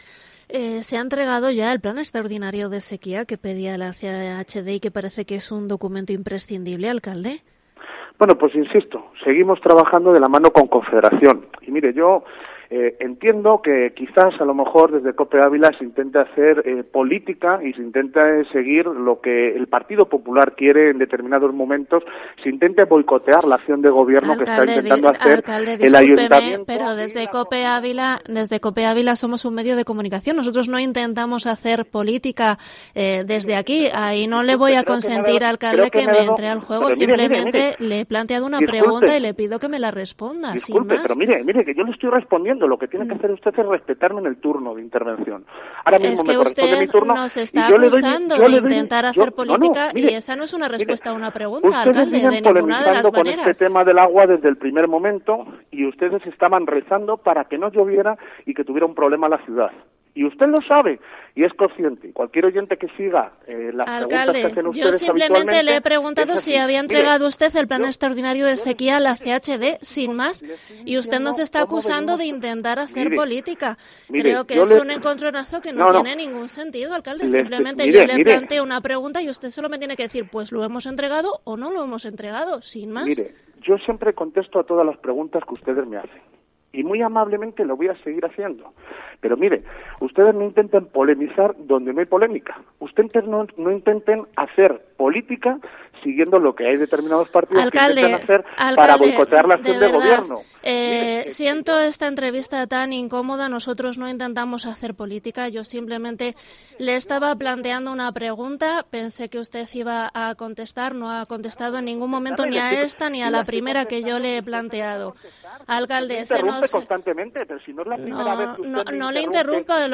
Desagradable momento de tensión el vivido hoy en Cope Ávila con el alcalde de la ciudad, Jesús Manuel Sánchez Cabrera, cuando invitado en Mediodía Cope y preguntado por el tema del abastecimiento del agua a la capital, ha acusado a este medio de comunicación de hacer política con este asunto y seguir las directrices del Partido Popular (ESCUCHAR AUDIO COMPLETO).